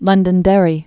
(lŭndən-dĕrē, lŭndən-dĕrē)